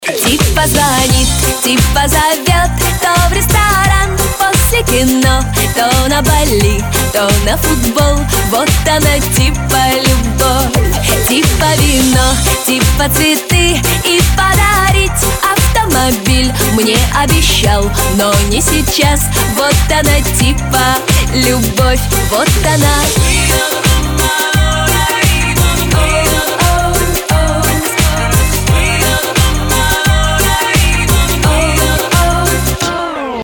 поп
женский вокал
эстрадные